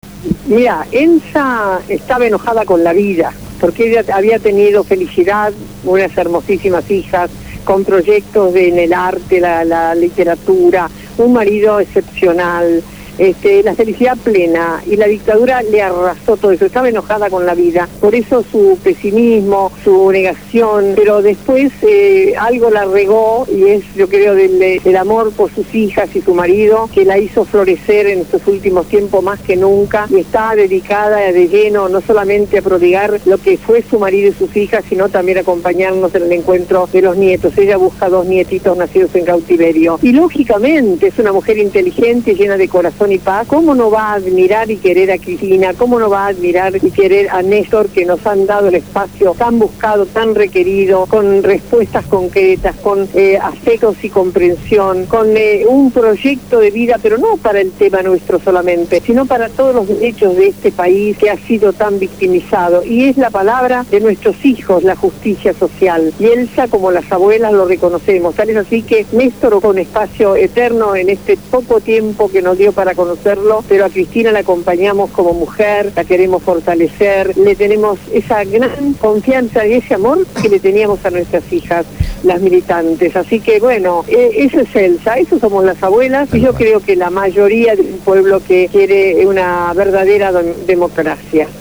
Elsa Sánchez de Oesterheld fue homenajeada en el Salón San Martín de la Legislatura de la Ciudad de Buenos Aires.